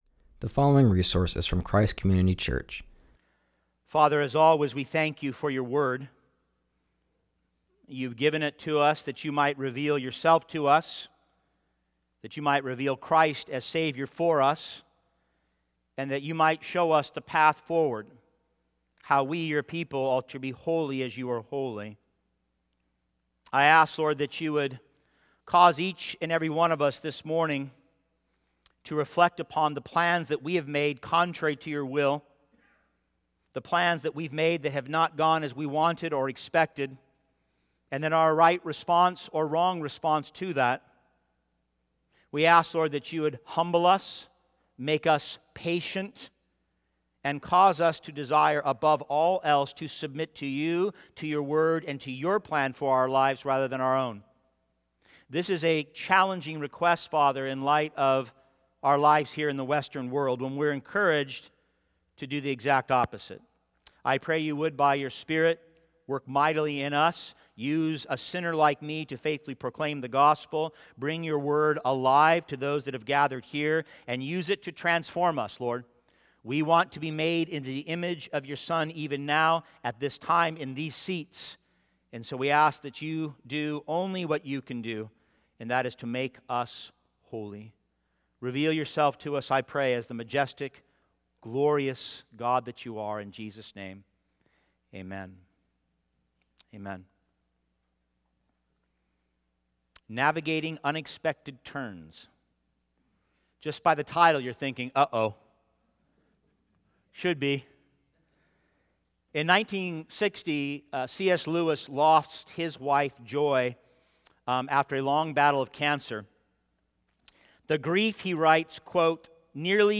preaches from Genesis 29:1-30